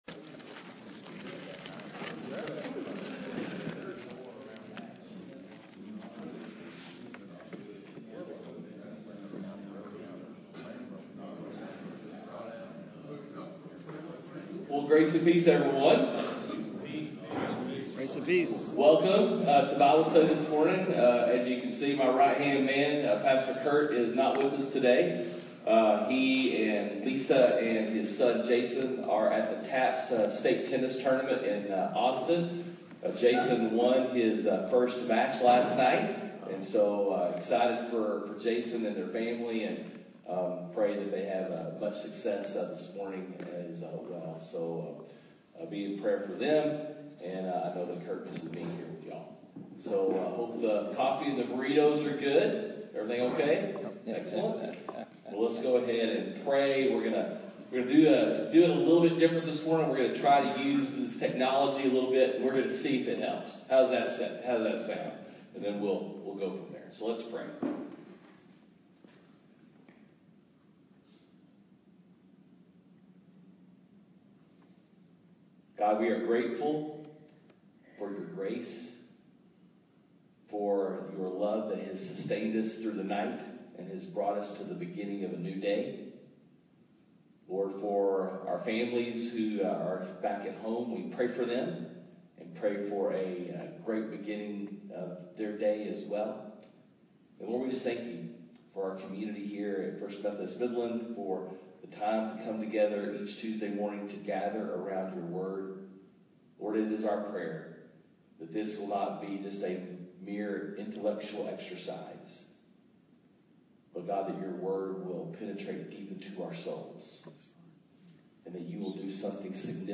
Men’s Breakfast Bible Study 10/20/20